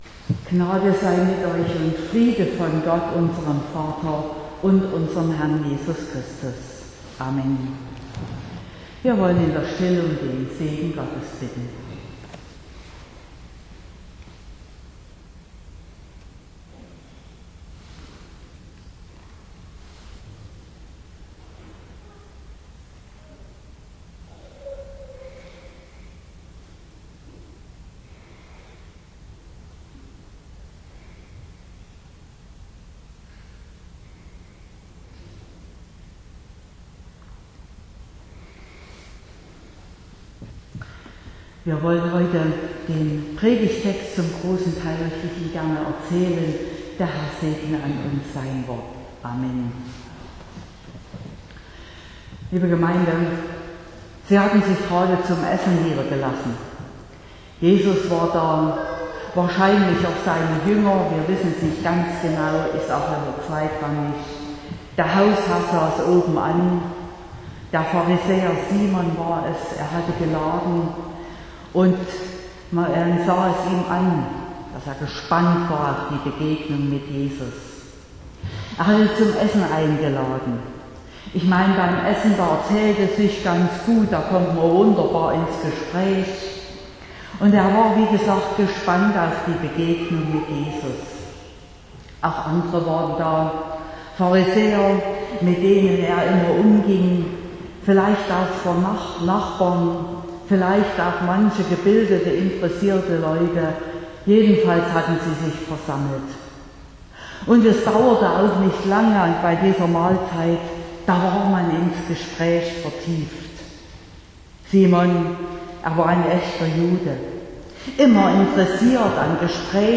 20.08.2023 – Gottesdienst
Predigt (Audio): 2023-08-20_Salbung_durch_die_Suenderin_-_mein_Blick_auf_Andere.mp3 (25,3 MB)